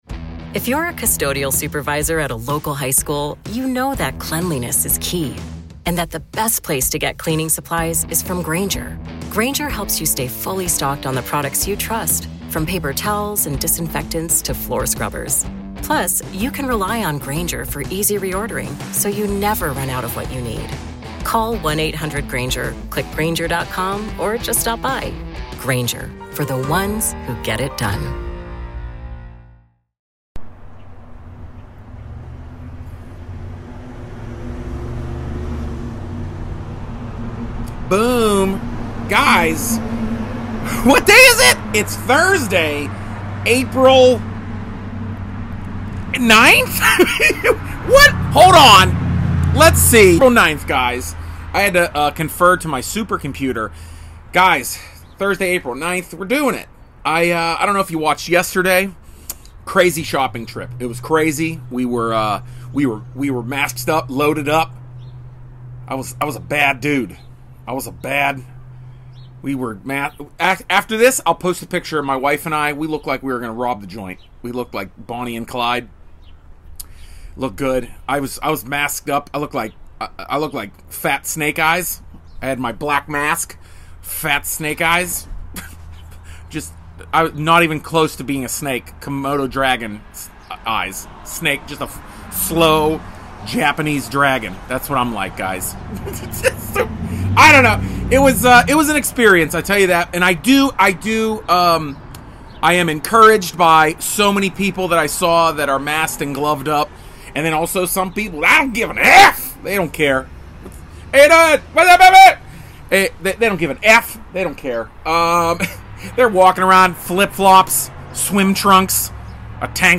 On my porch pretty much in the morning drinking coffee.